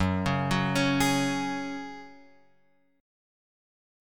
F#sus2 chord {2 4 4 x 2 4} chord